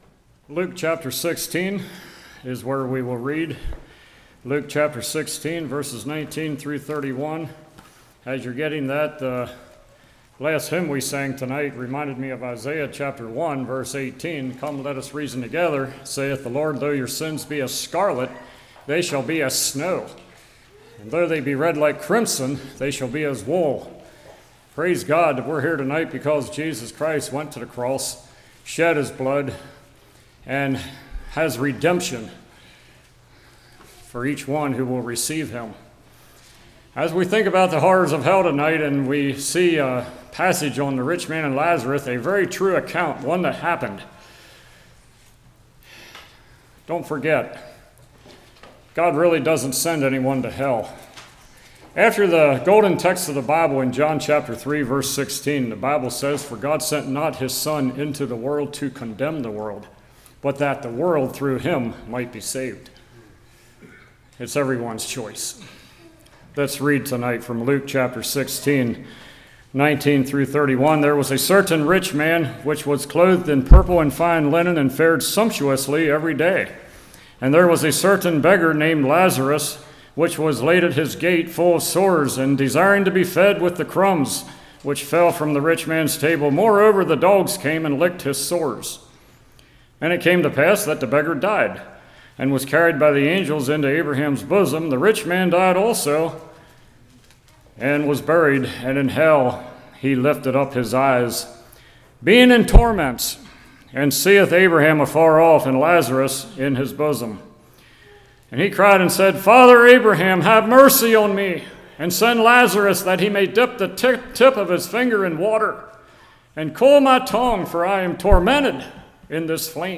Luke 16:19-31 Service Type: Revival What Would Hell be Like?